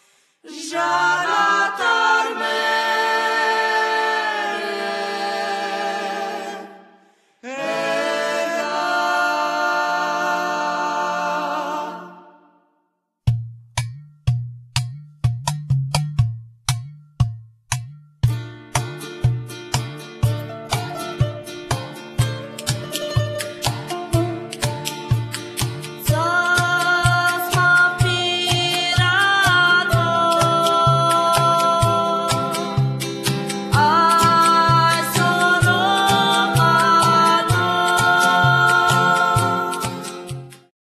jak też pieśni cygańskie